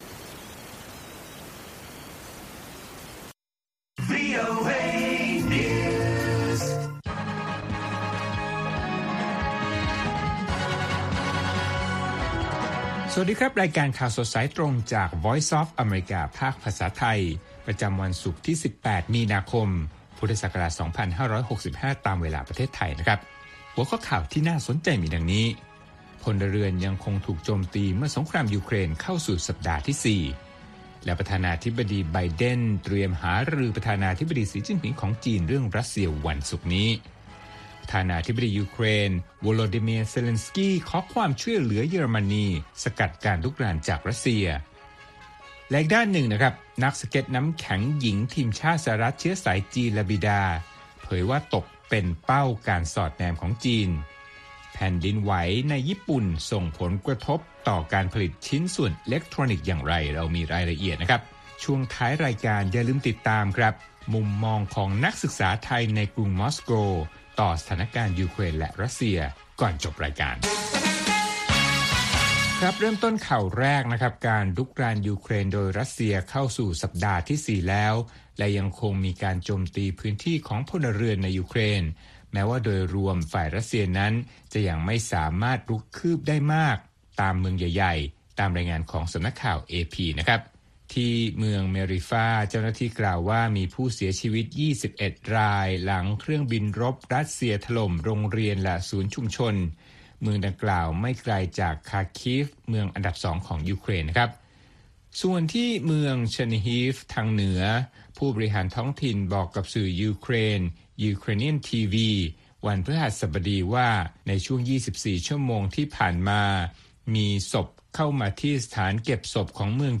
ข่าวสดสายตรงจากวีโอเอ ภาคภาษาไทย ประจำวันศุกร์ที่ 18 มีนาคม 2565 ตามเวลาประเทศไทย